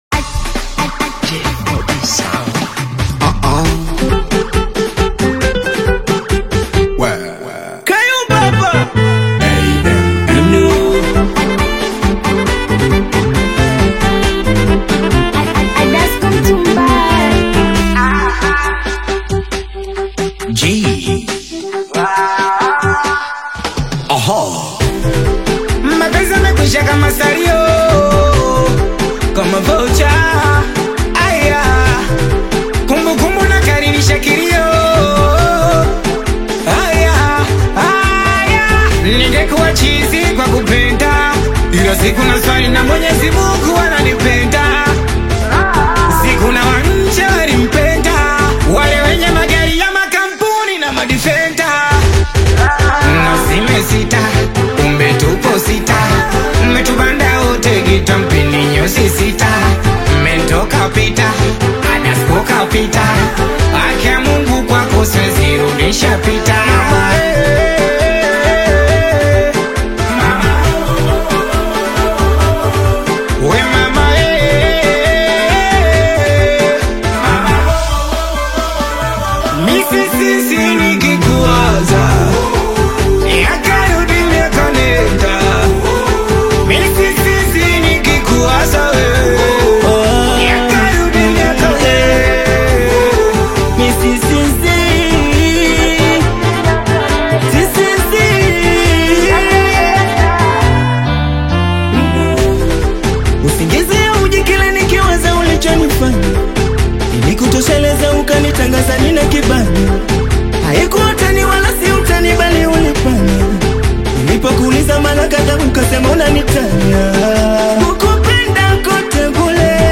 high-energy Tanzanian Singeli/Bongo Flava collaboration
dynamic delivery and authentic urban sound